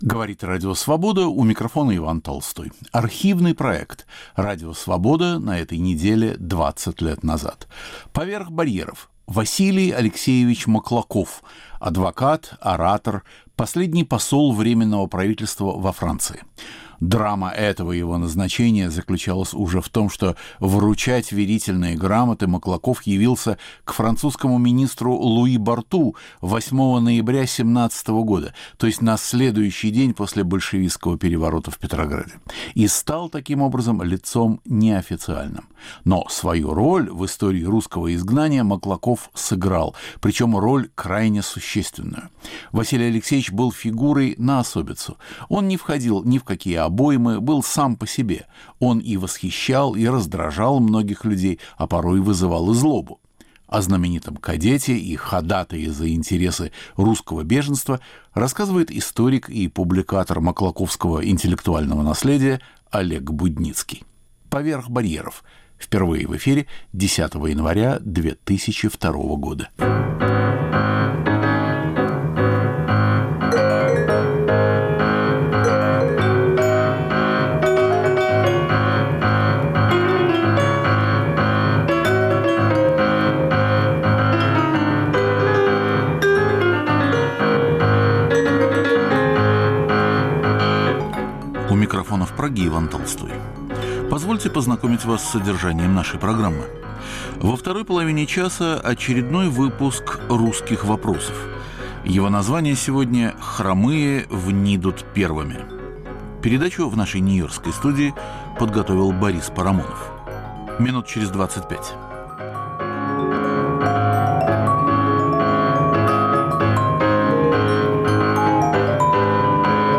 О знаменитом кадете и ходатае за интересы русского беженства рассказывает историк